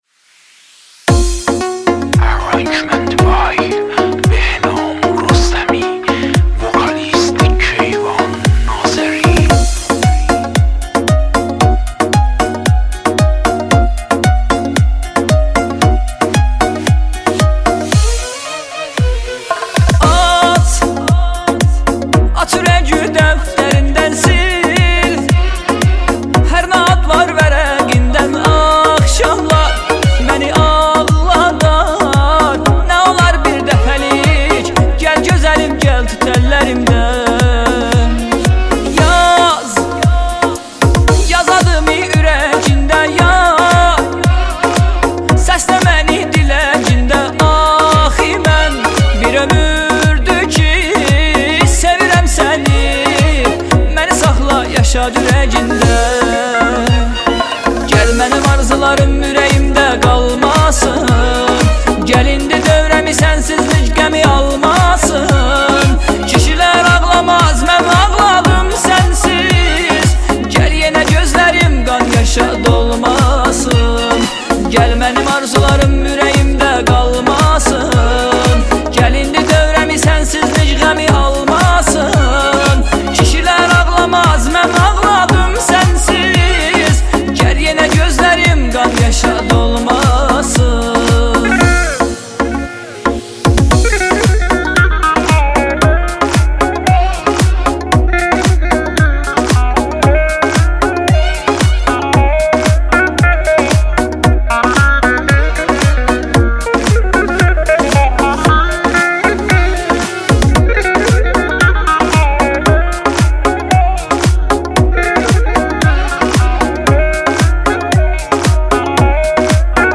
دانلود آهنگ آذری
دانلود آهنگ ترکی آذری معروف اینستاگرام